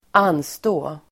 Uttal: [²'an:stå:]